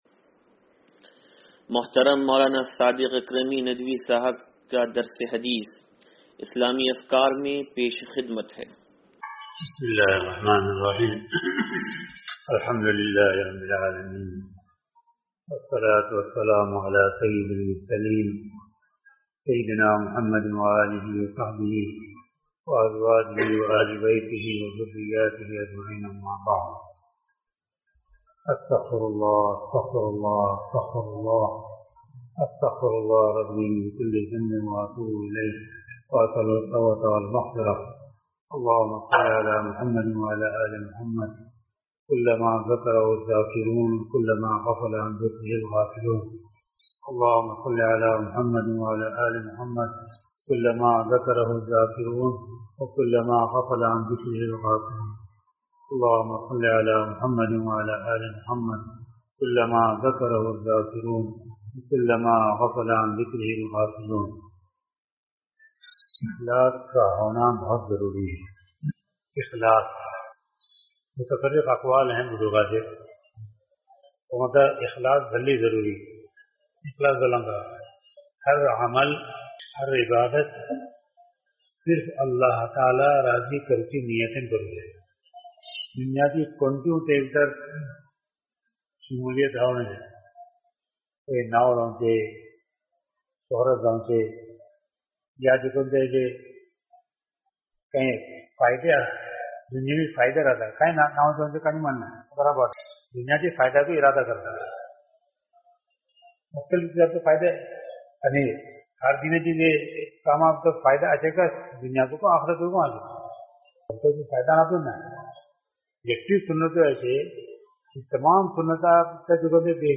درس حدیث نمبر 0453